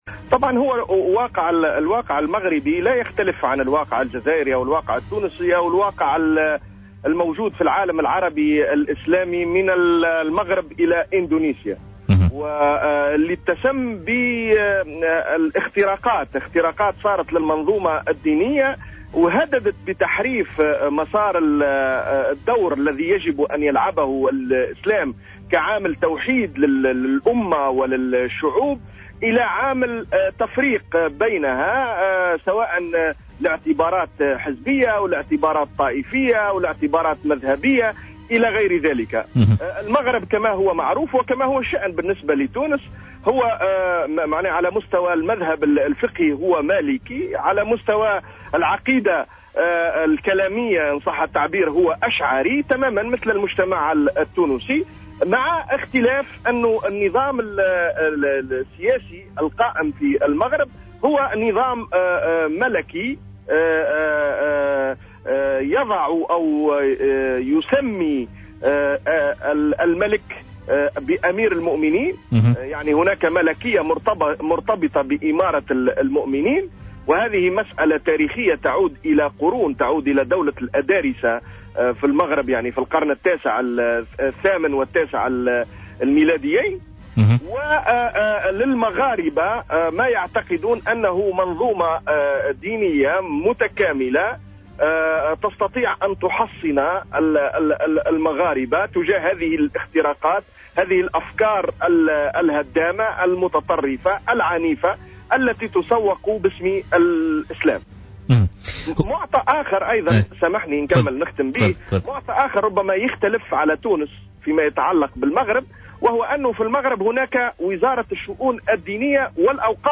علّق خالد شوكات،الكاتب والناشط السياسي في مداخلة له اليوم في برنامج "بوليتيكا" على القانون الذي صدر مؤخرا في المغرب لمنع رجال الدين من ممارسة أي نشاط سياسي.